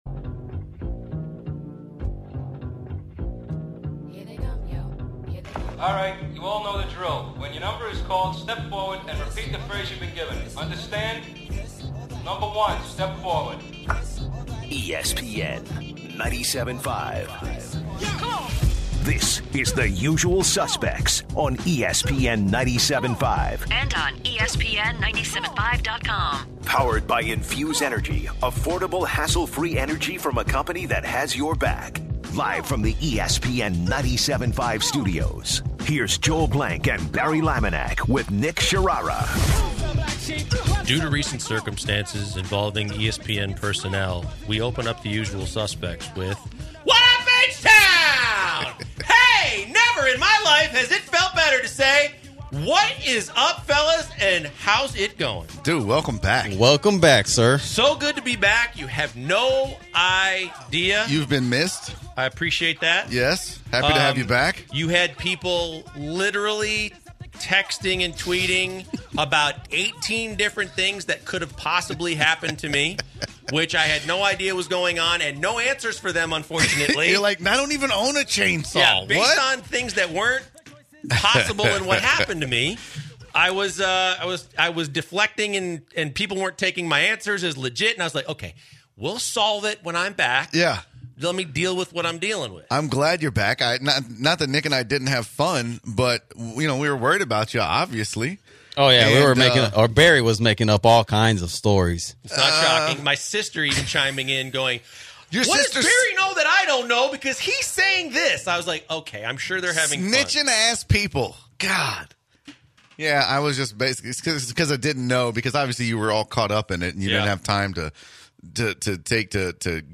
back in the studio